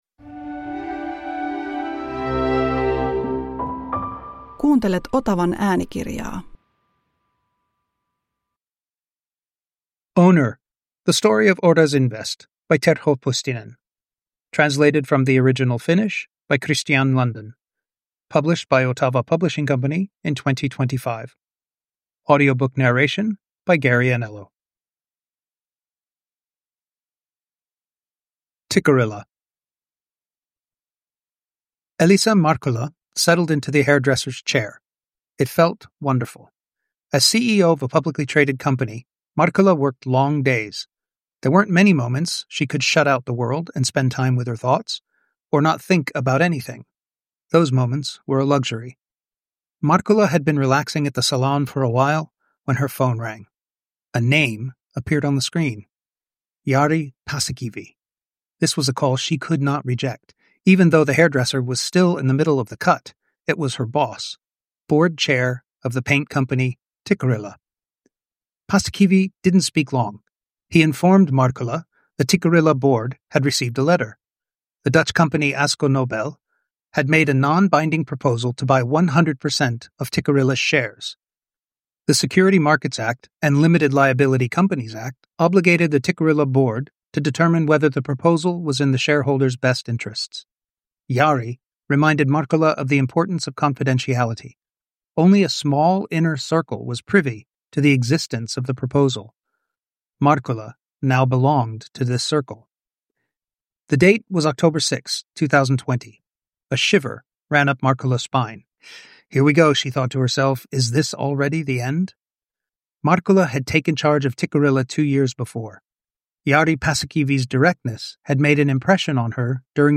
Owner – The Story of Oras Invest – Ljudbok